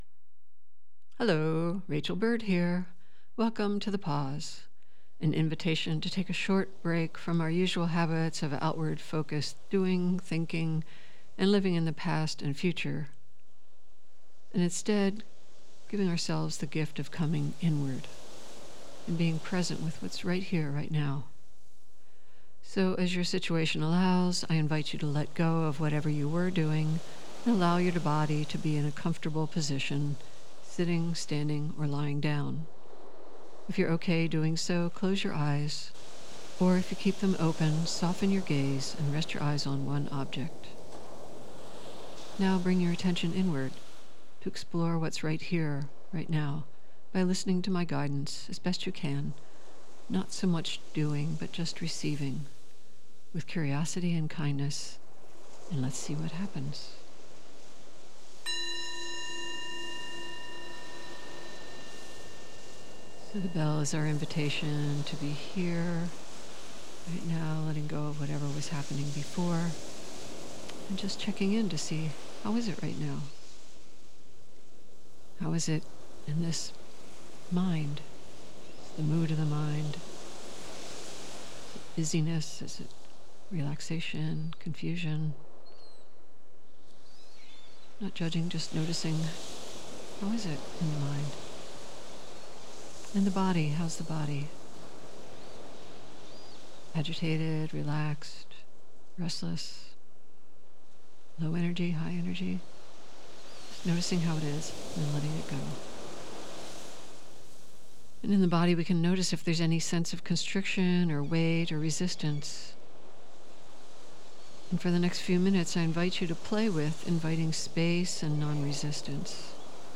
Meditation: inviting the mind to be clear and open like the sky.